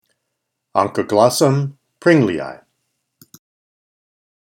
Pronunciation:
On-ca-glós-sum príng-le-i